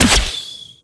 bow_fire_01.wav